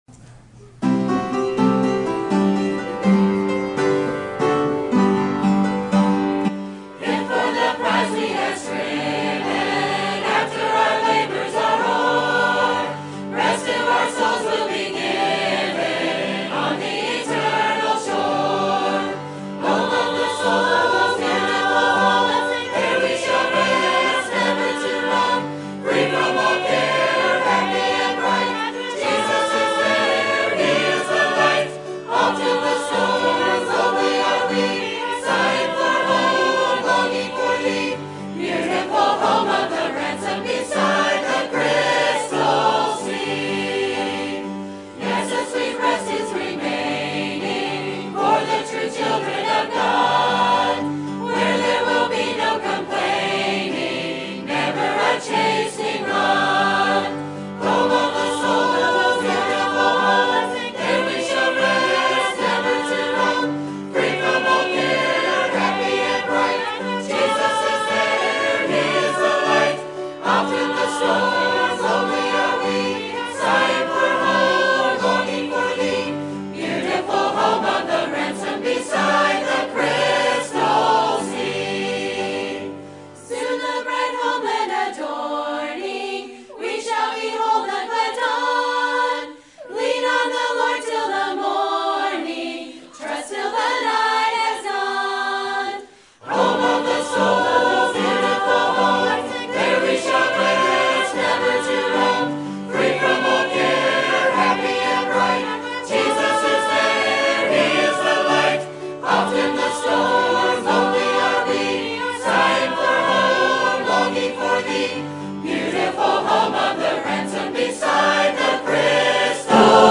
Sermon Topic: Baptist History Conference Sermon Type: Special Sermon Audio: Sermon download: Download (41.22 MB) Sermon Tags: Isaiah Baptist History Liberty